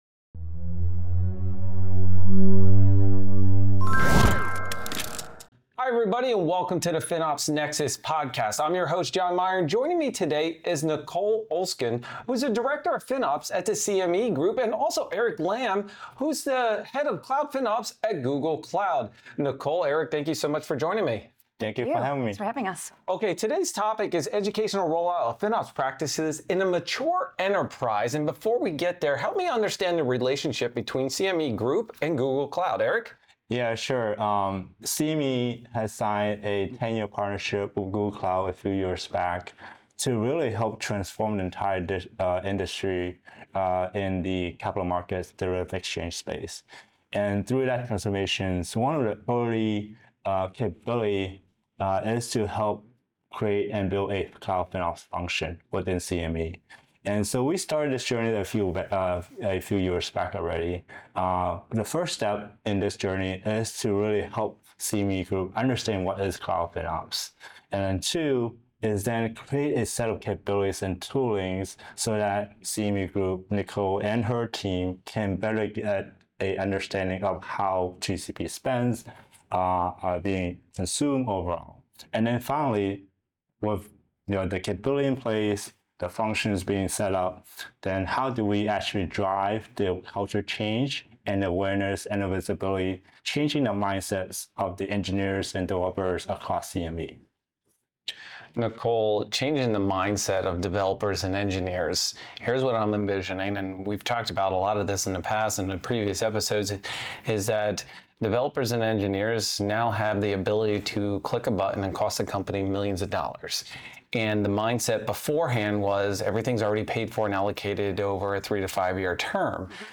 Join us on this episode of the FinOps NEXUS Podcast, live from FinOpsX